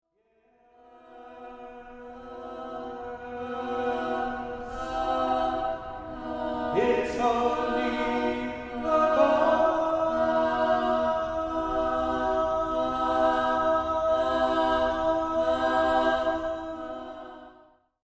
Over the two days, participants are introduced to the basic elements of improvised singing including melody, harmony, text, pulse and vocal percussion.
Sample files from Creative Voice workshops
Creative Voice Improv 2.mp3